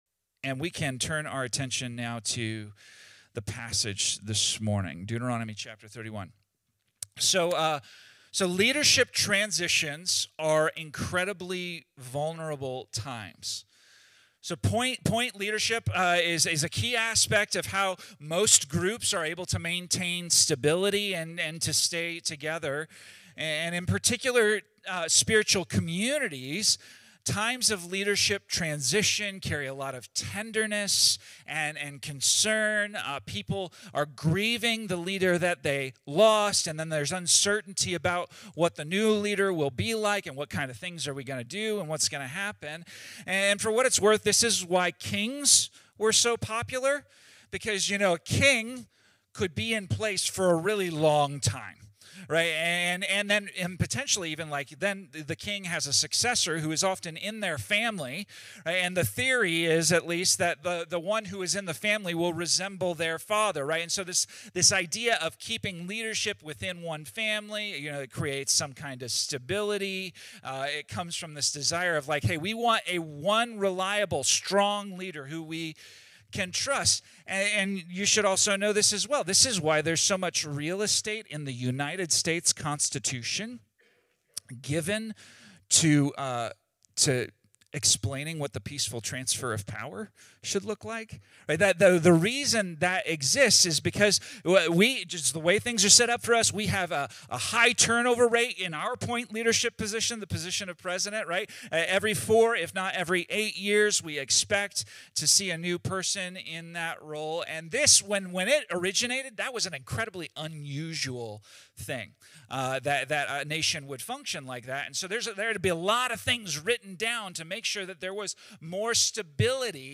This message from the Death of Moses series dives into Deuteronomy 31, where Moses faces his final days and hands leadership to Joshua. As Israel prepares for a major transition, God calls His people to “be strong and courageous” in the face of fear, loss, and uncertainty. The sermon shows that real courage isn’t about self-confidence—it’s about remembering God’s faithfulness, staying humble, clinging to His Word, and resisting the pull of a faithless culture.